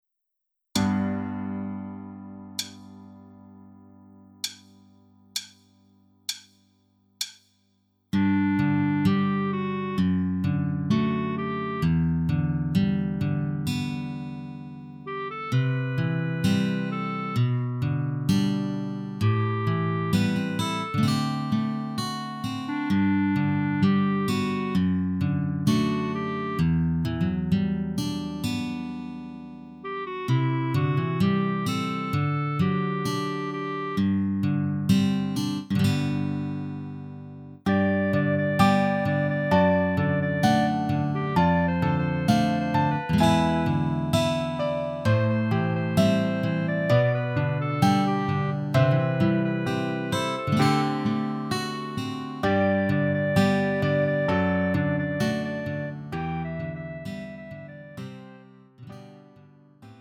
음정 -1키 3:59
장르 구분 Lite MR